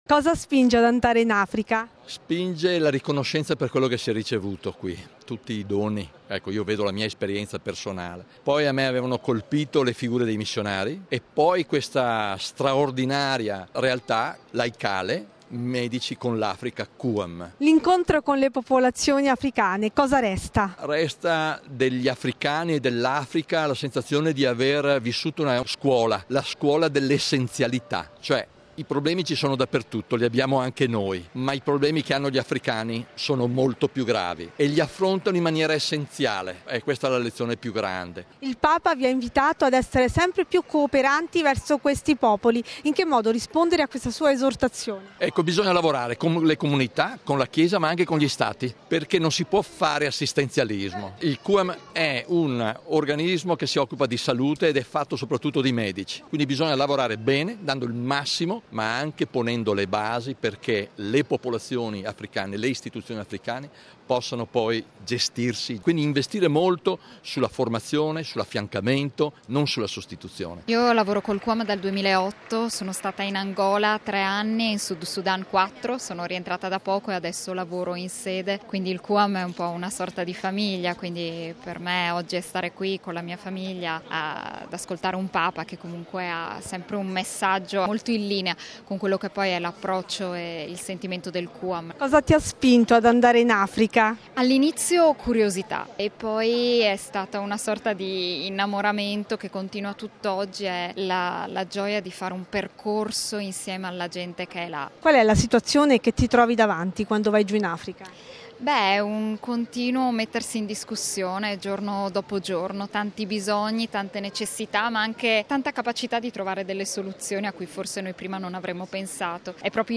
Bollettino Radiogiornale del 07/05/2016